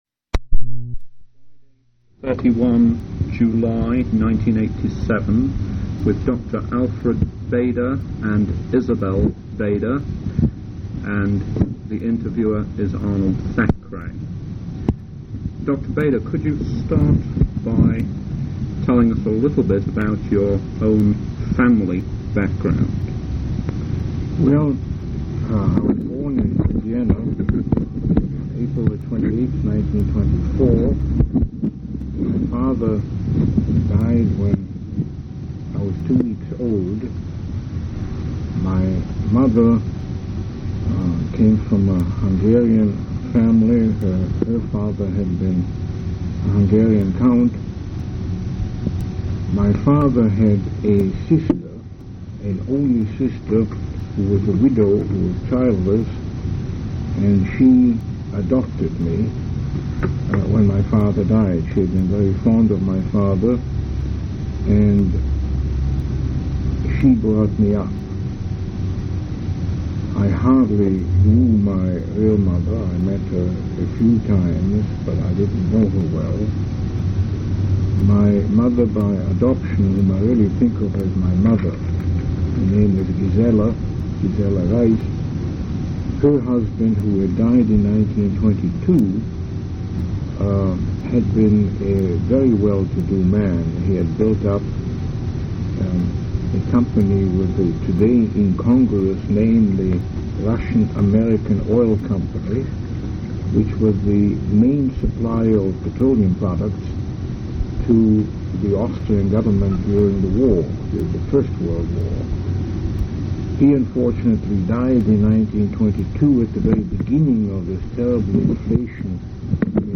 Oral history interview with Alfred R. Bader